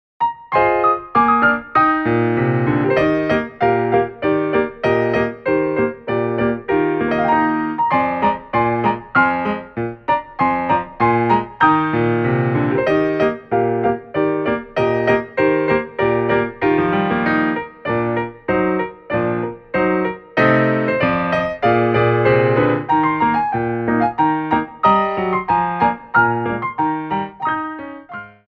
Petit Allegro 1
2/4 (16x8)